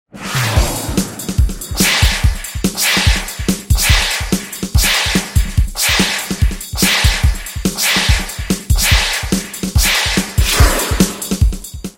Contagem regressiva 9s Animação sound effects free download
Contagem regressiva 9s - Animação no túnel